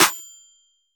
Clap [ March Madness ].wav